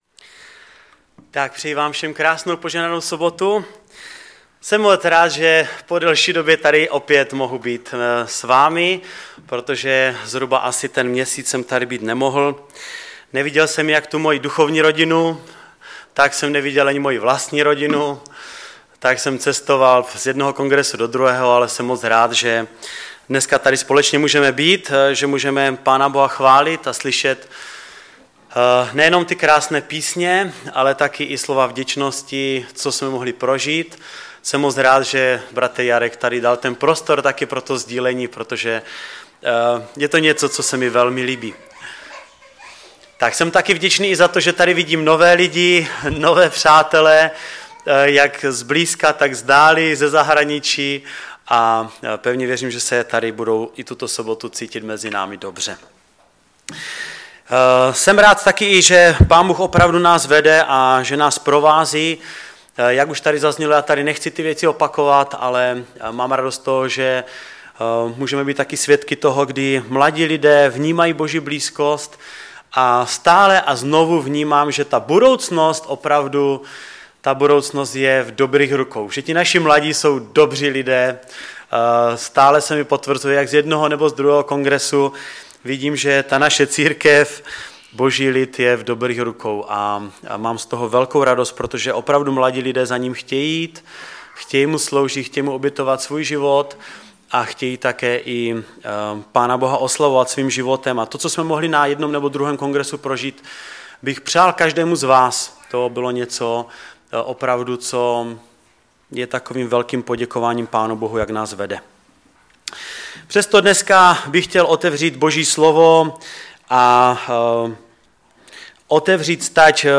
Kázání
Kazatel